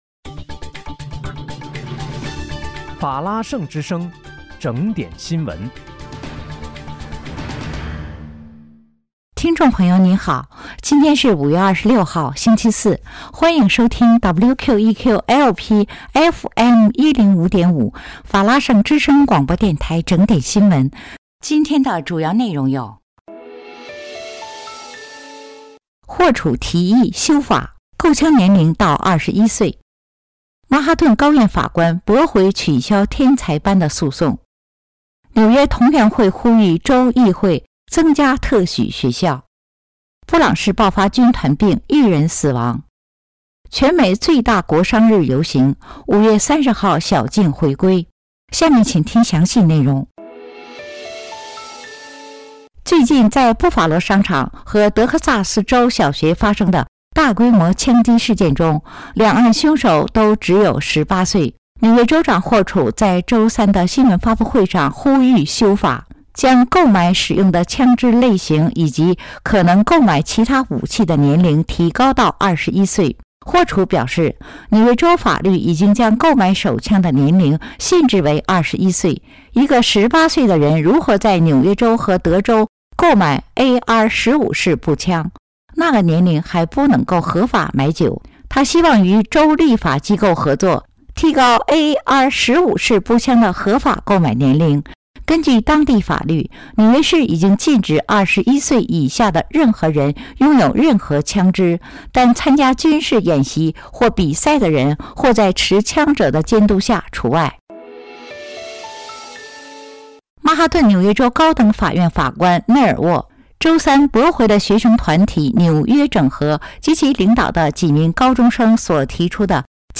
5月26日（星期四）纽约整点新闻